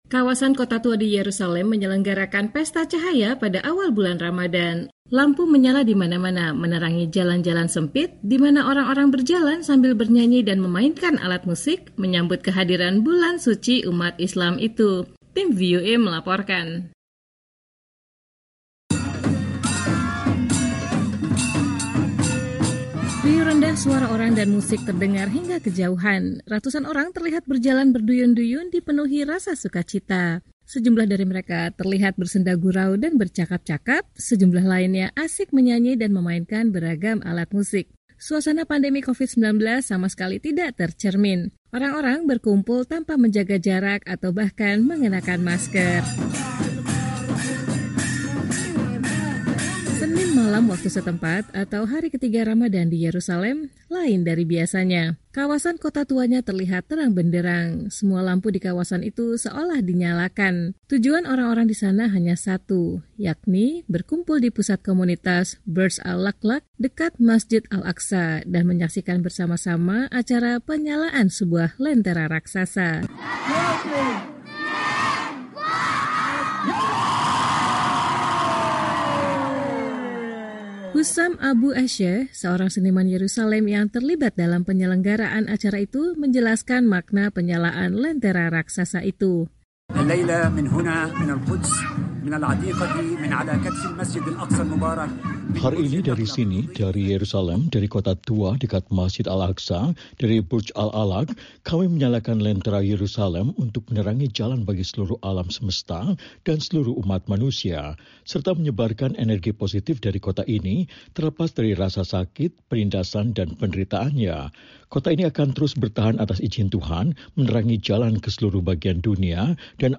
Kawasan Kota Tua di Yerusalem menyelenggarakan pesta cahaya pada awal bulan Ramadan. Lampu menyala di mana-mana, menerangi jalan-jalan sempit, di mana orang-orang berjalan sambil bernyanyi dan memainkan alat musik, menyambut kehadiran bulan suci umat Islam itu.
Riuh rendah suara orang dan musik terdengar hingga kejauhan.